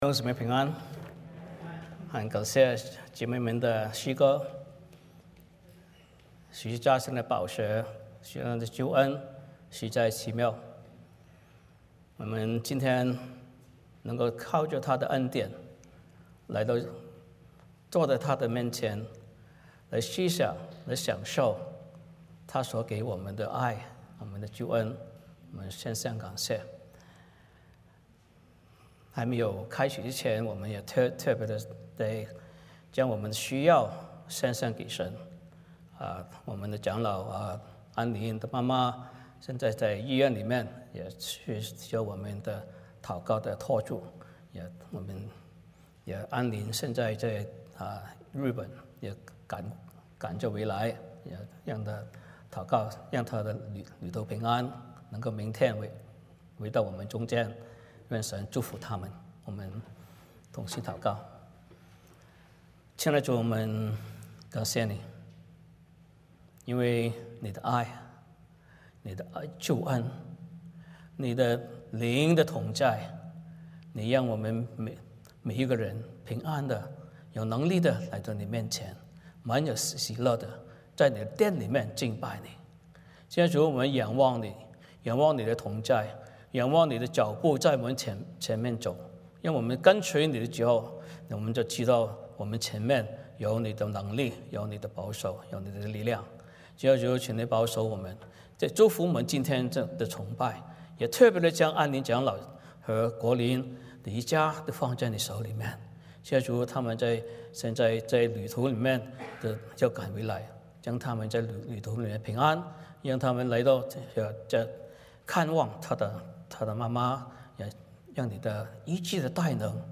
马太福音 1:18-25 Service Type: 主日崇拜 欢迎大家加入我们的敬拜。